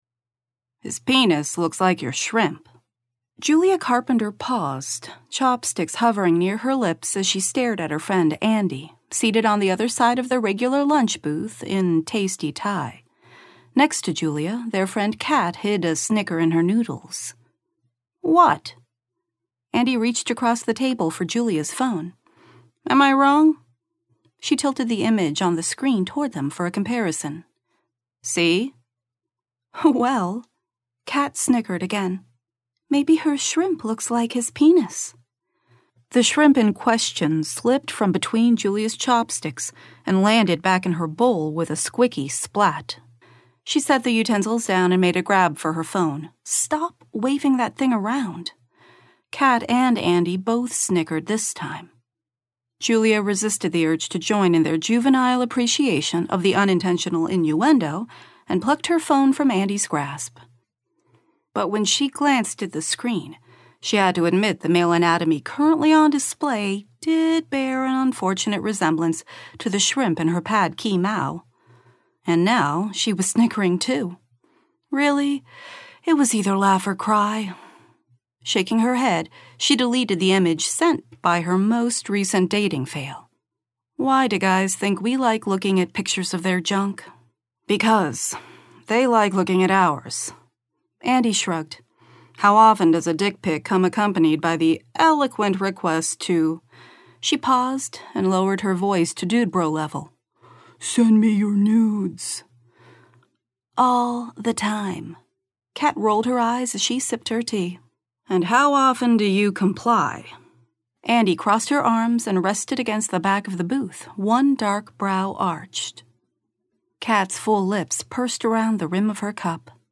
Related Audiobooks